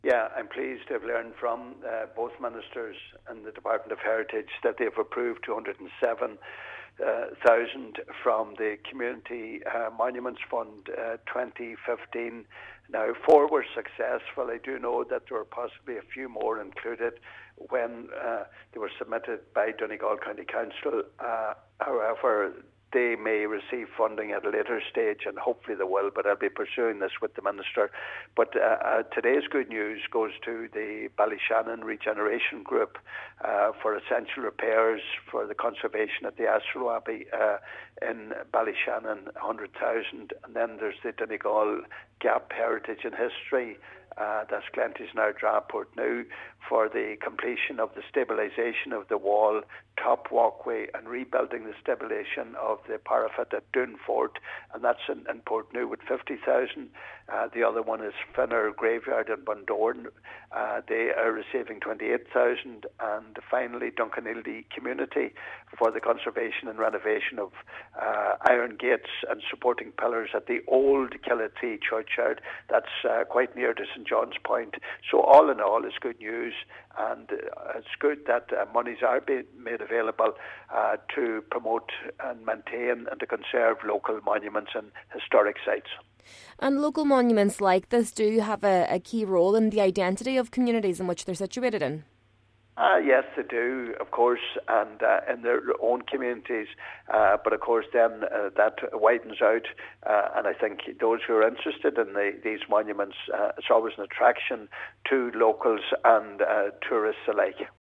Donegal Deputy Pat the Cope Gallagher says he will push to see that unsuccessful applicants receive funding down the line: